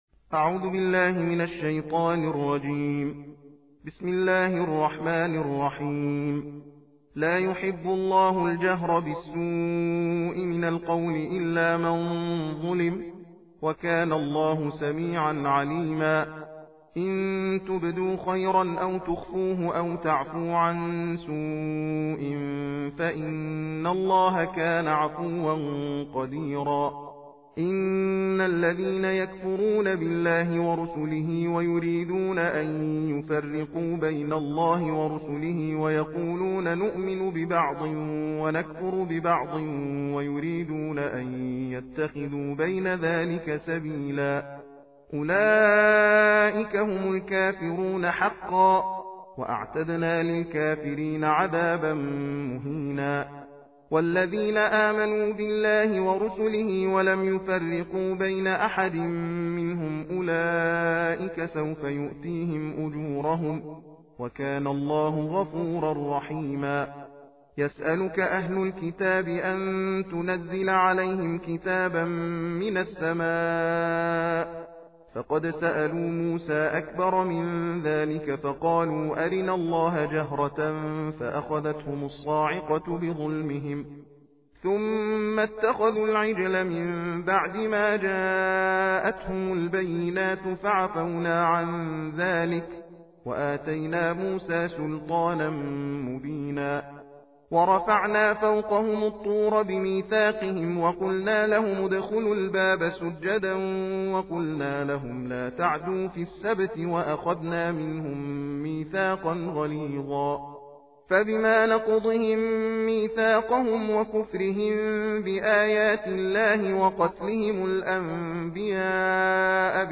صوت/تلاوت جزء ششم قرآن کریم